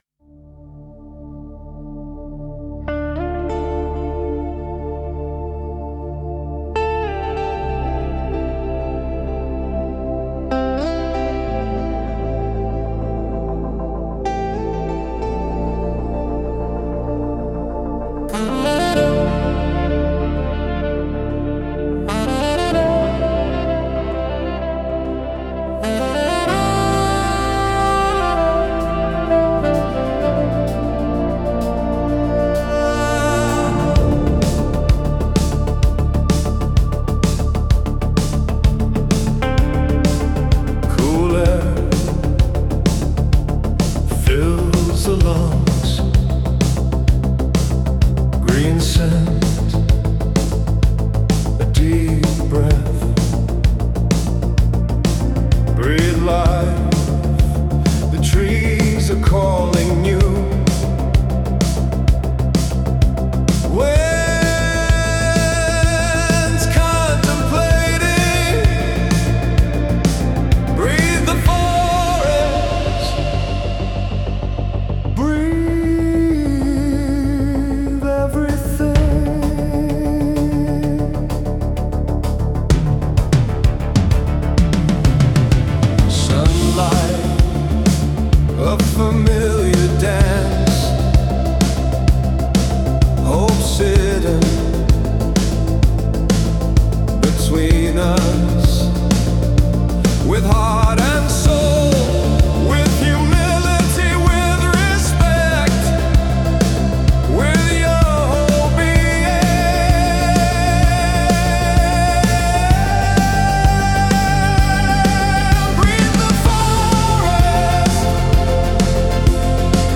Balady, romance
Anotace: mp3 je anglická verzia
Hudba a spev AI